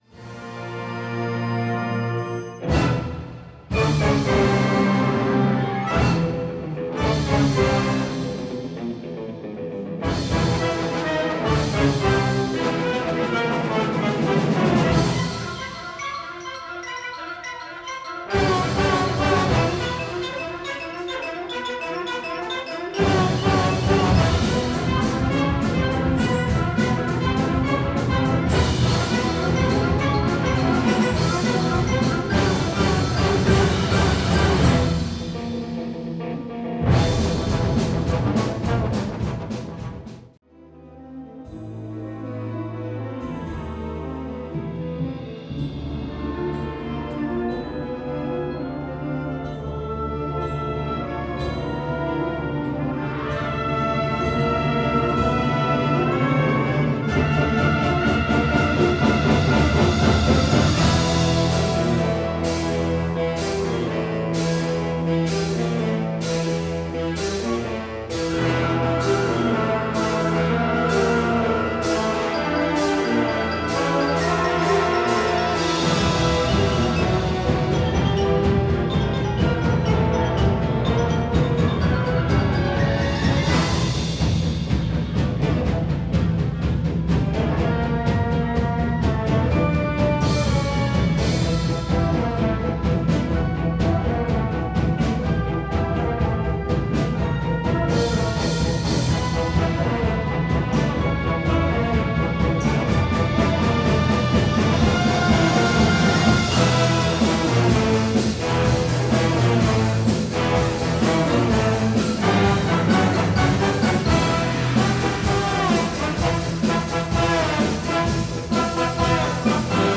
Herbstkonzert 2024